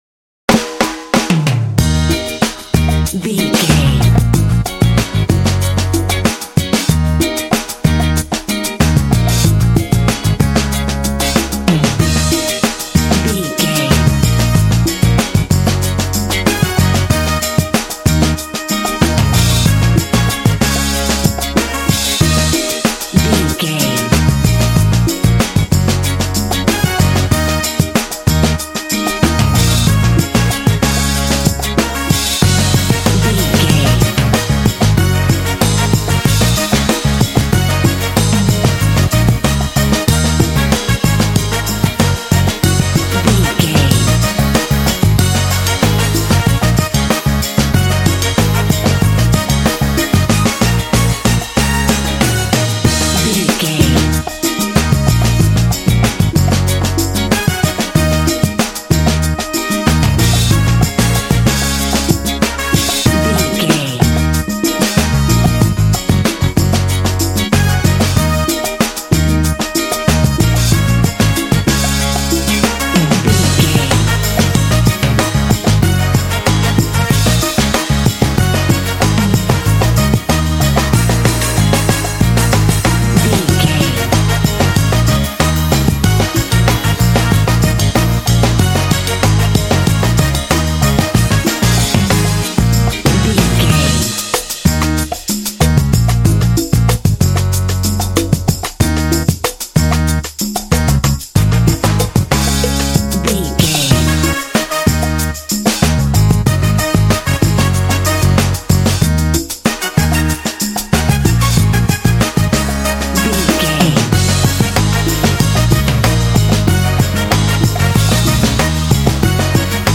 This cool and funky track is great for action games.
Aeolian/Minor
E♭
groovy
funky
driving
saxophone
drums
percussion
bass guitar
electric guitar
brass